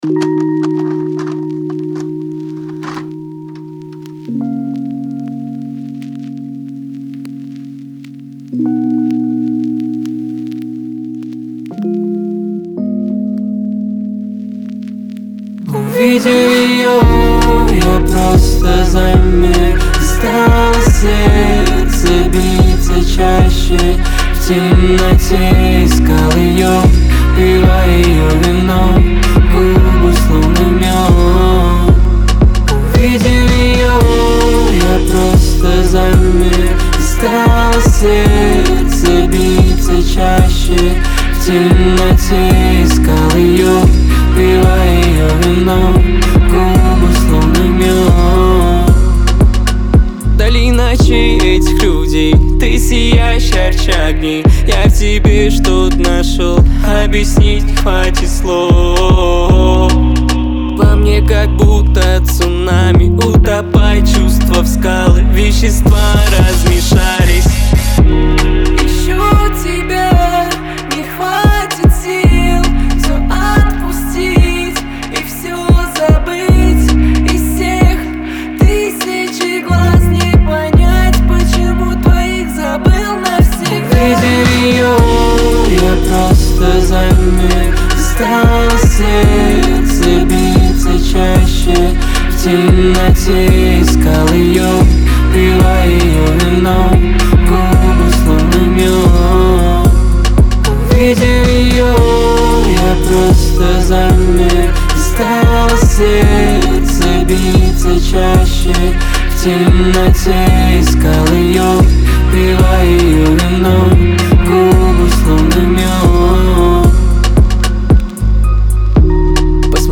в жанре поп